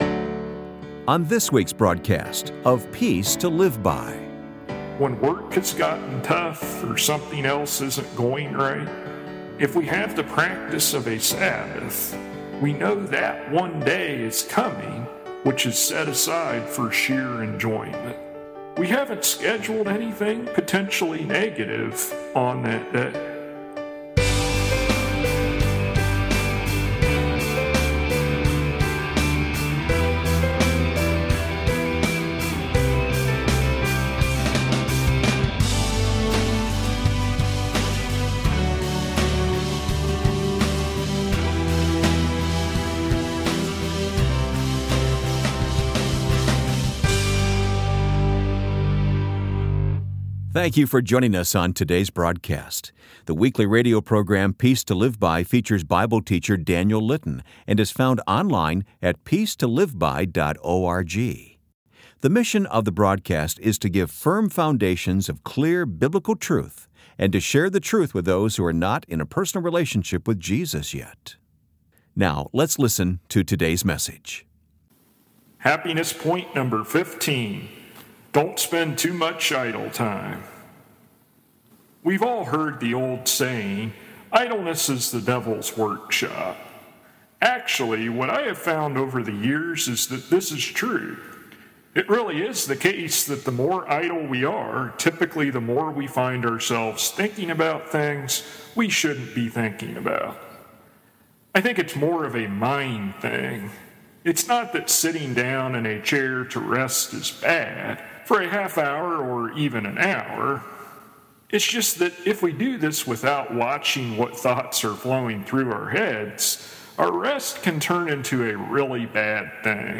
[Transcript may not match broadcasted sermon word for word]